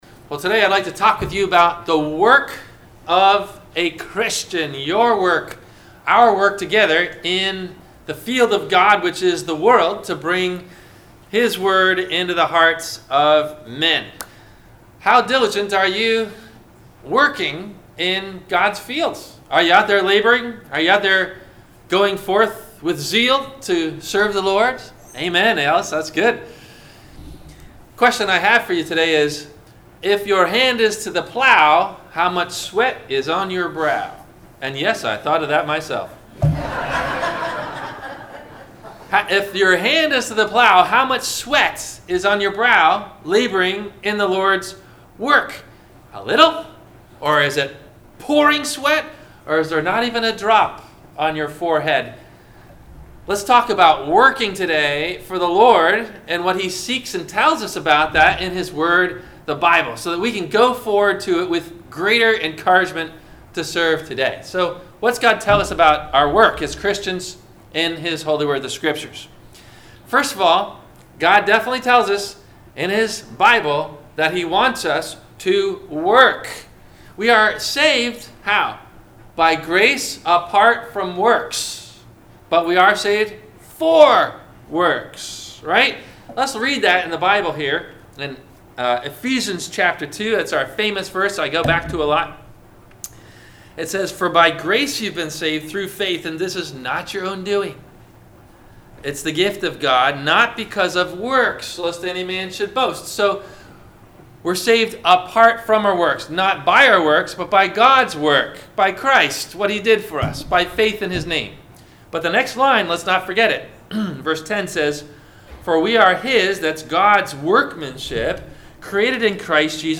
- Sermon - February 17 2019 - Christ Lutheran Cape Canaveral